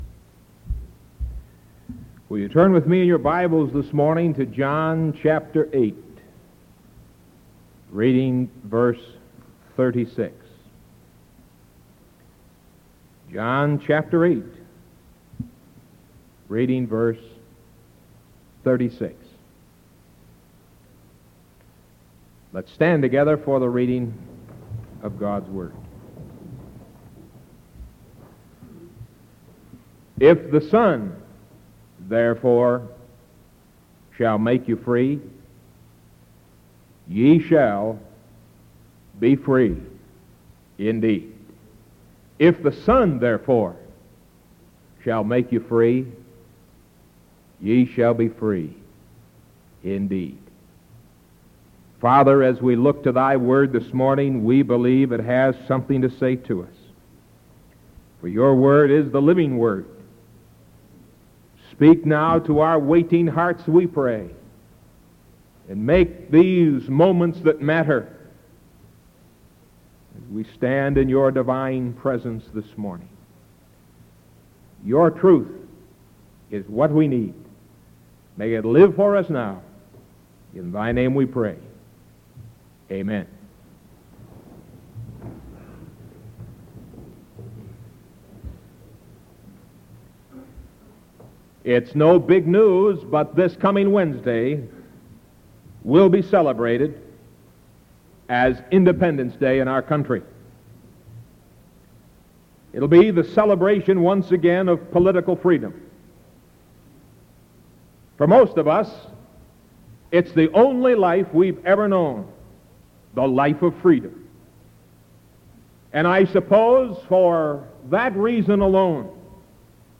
Sermon July 1st 1973 AM